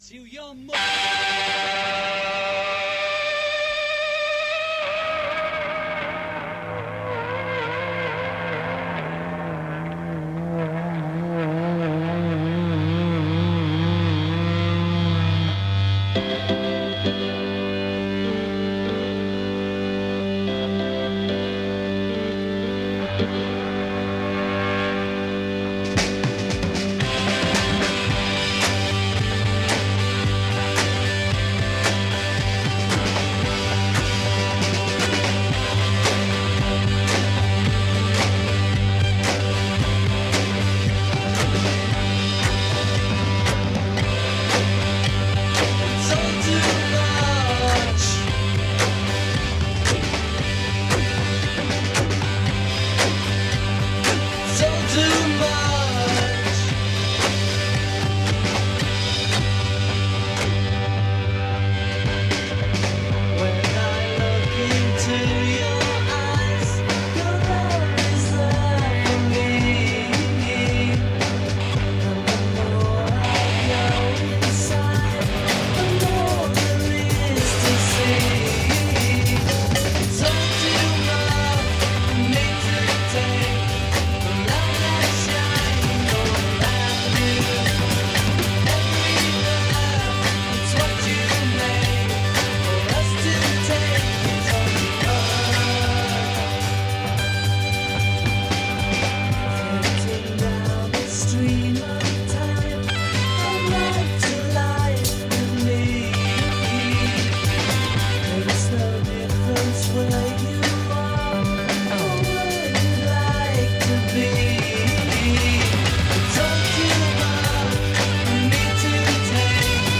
hour-long set